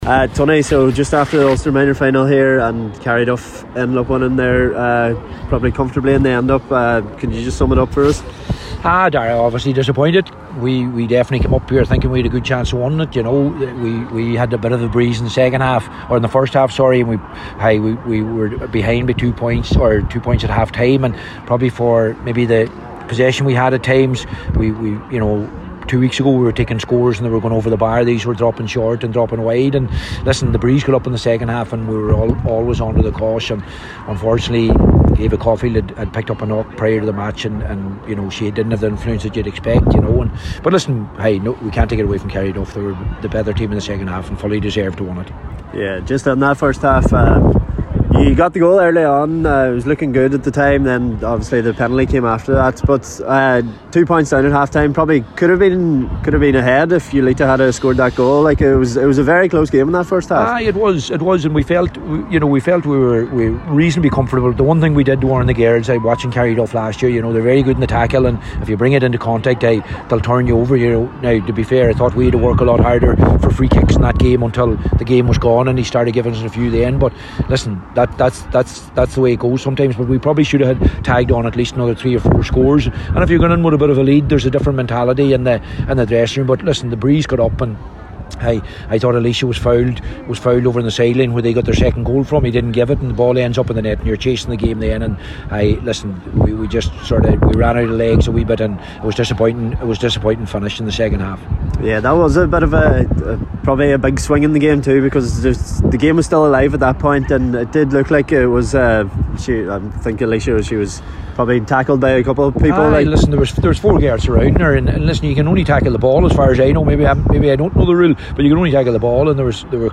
after the game…